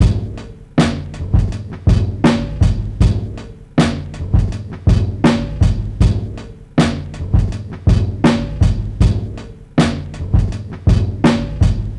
cowboy80bpm.wav